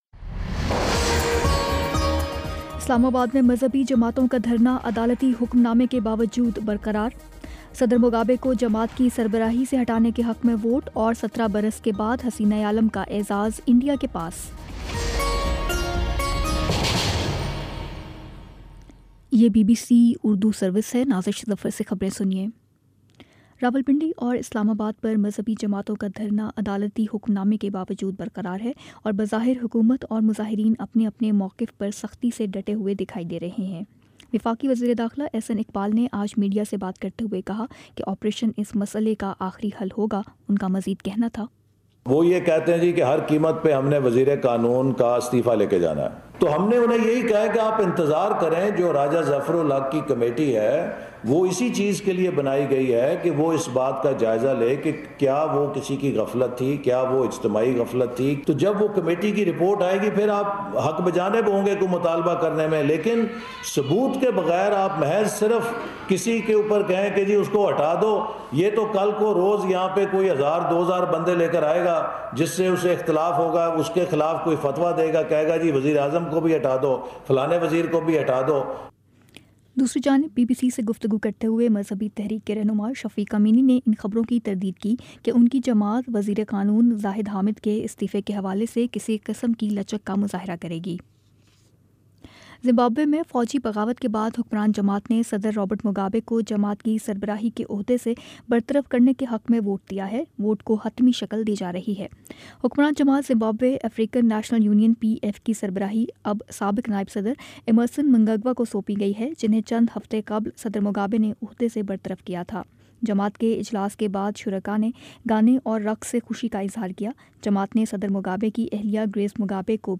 نومبر 19 : شام سات بجے کا نیوز بُلیٹن